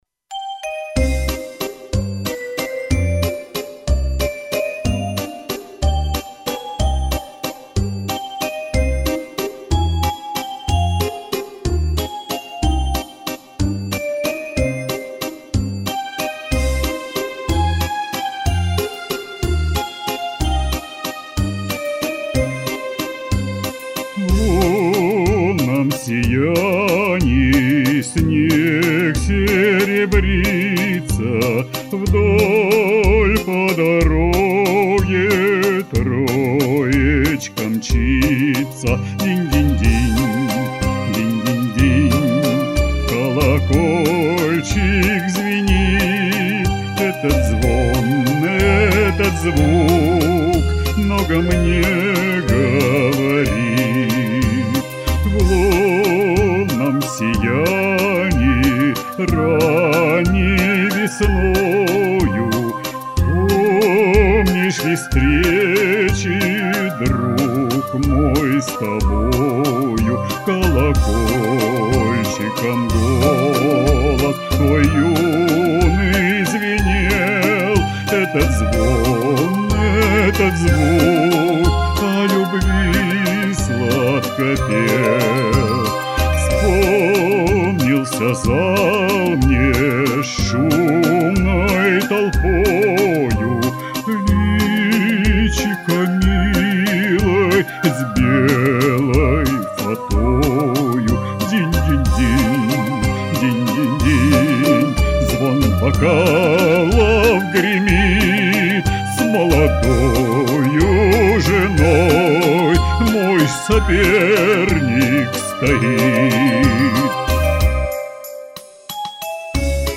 Вокальный ринг.
Так вот , романс вы выбрали прекрасный, легкий , кружевной ... и теноровый.
Бесспорно замечательный голос, тембр...!